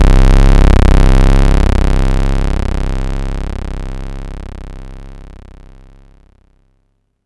Dark Hit C1.wav